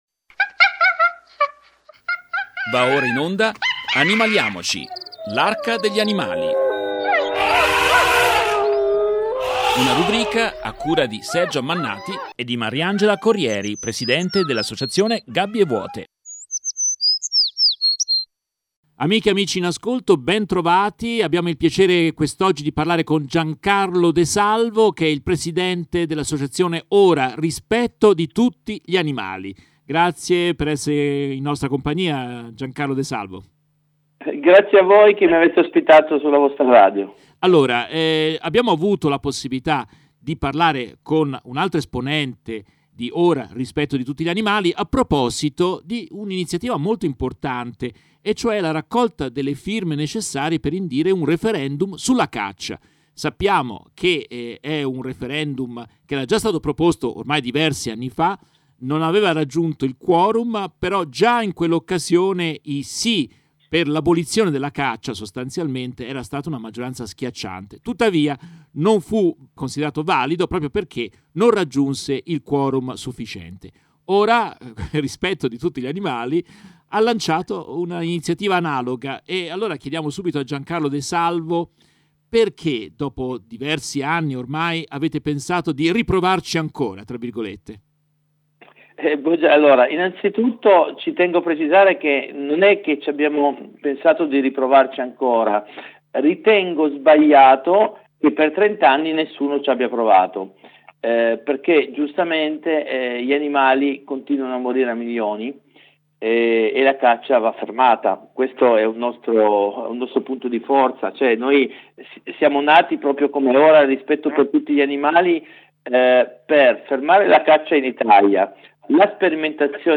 Con lui parliamo dell'imminente raccolta di 500.000 firme – in tre mesi – per dare la possibilità agli italiani di votare contro la caccia in un prossimo referendum nazionale. Intervista